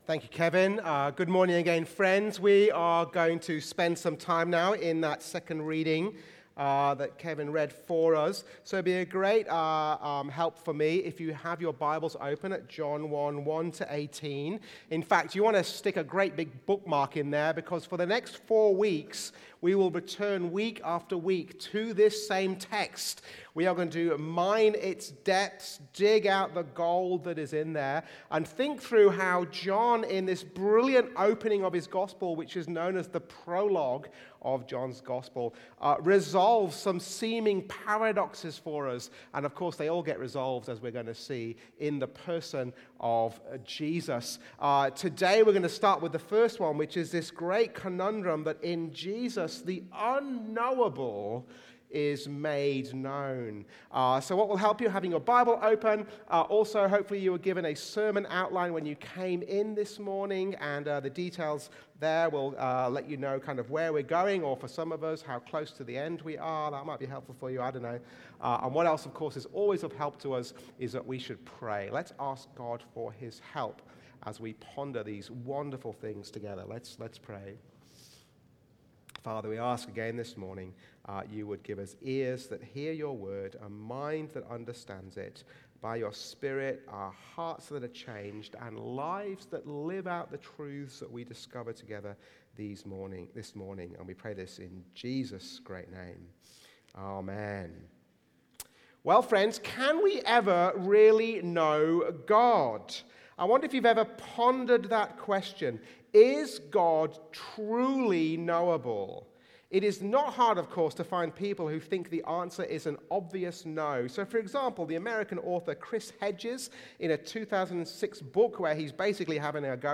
Sunday sermon
from St John’s Anglican Cathedral Parramatta